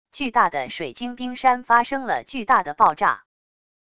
巨大的水晶冰山发生了巨大的爆炸wav音频